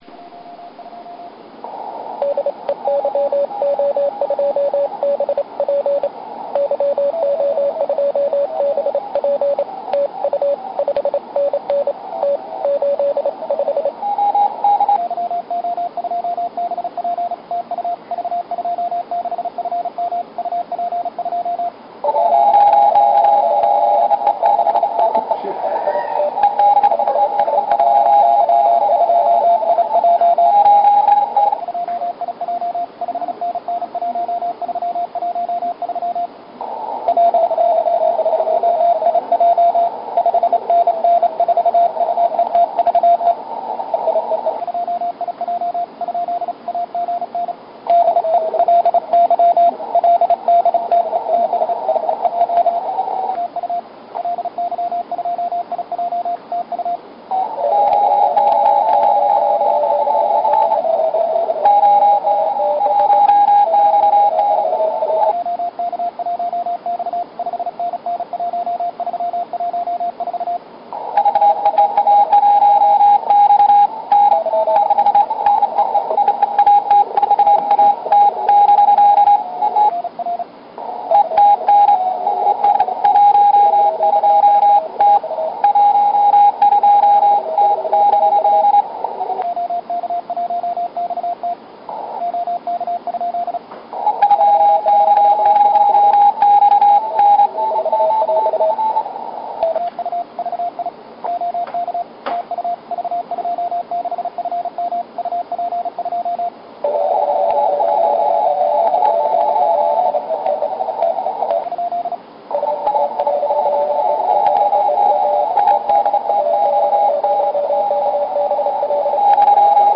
running 10 meter CW pile-up